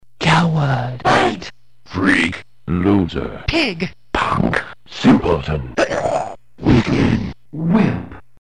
16 - bit sound samples
All Sound samples had a standard Hiss removal done to them, and the volume was increased to the same level for each.